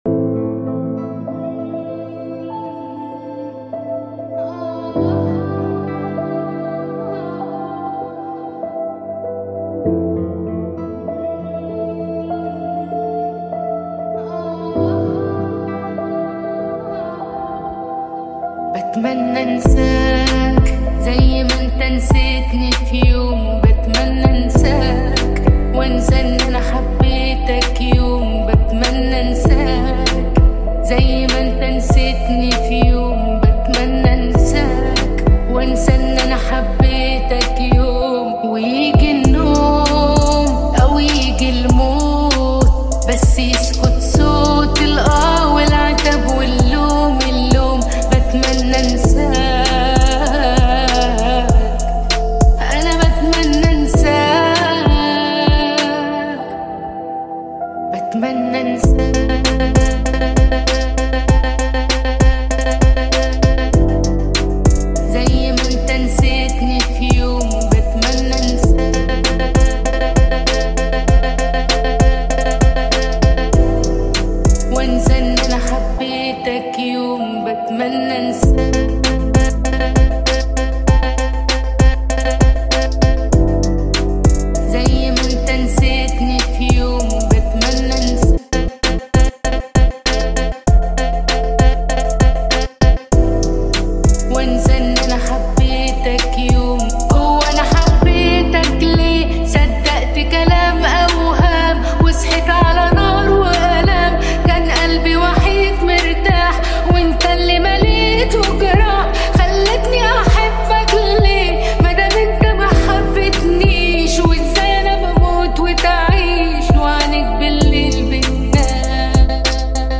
دانلود مستقیم نسخه ریمیکس با کیفیت اصلی